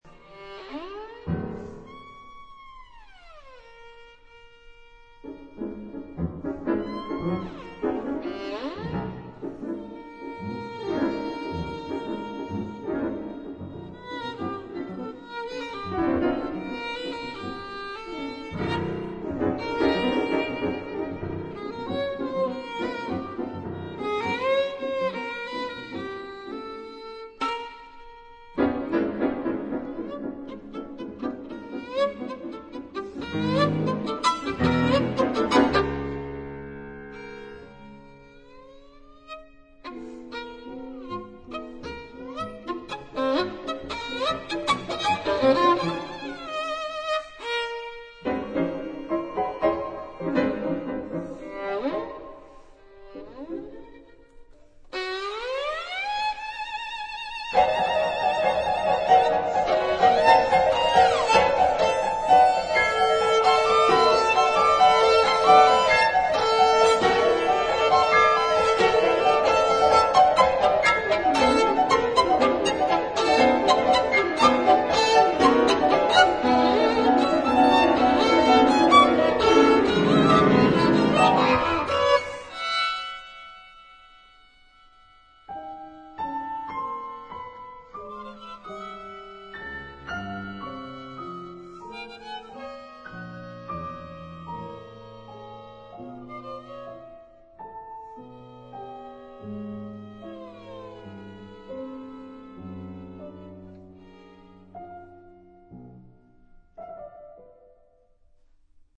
鋼琴獨奏